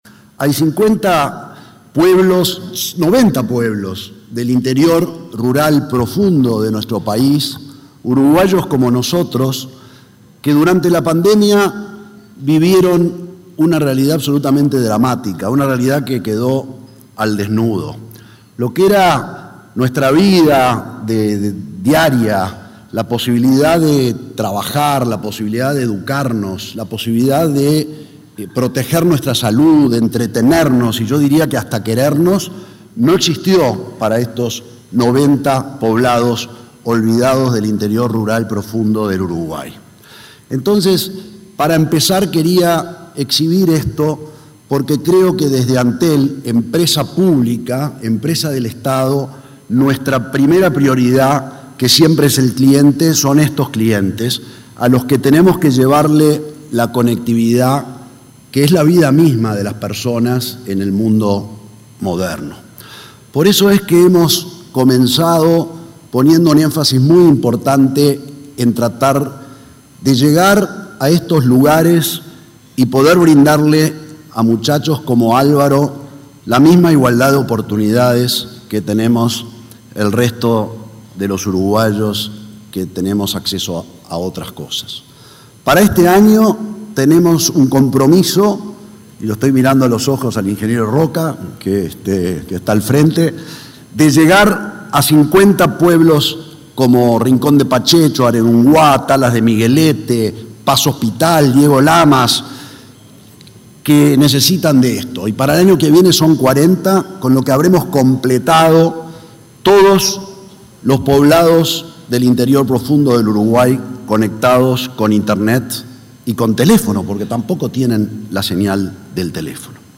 El presidente de Antel, Gabriel Gurméndez, disertó este miércoles 1.° en un desayuno de trabajo de la Asociación de Dirigentes de Marketing (ADM),